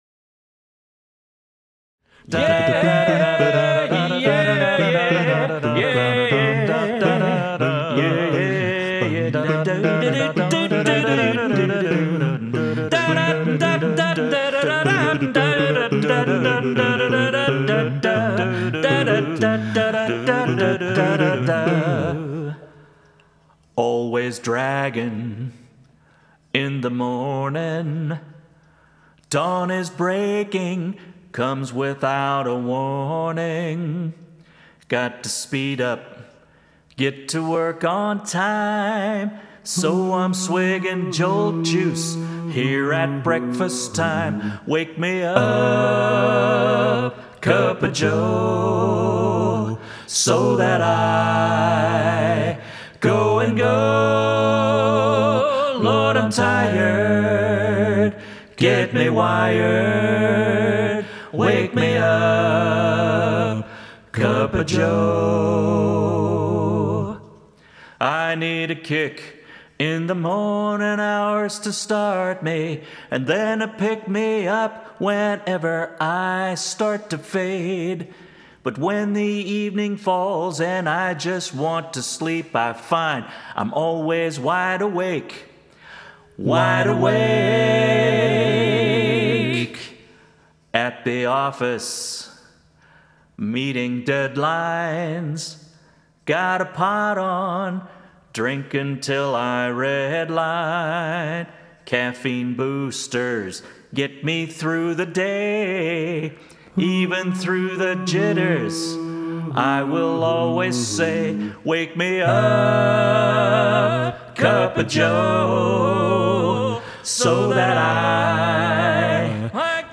a capella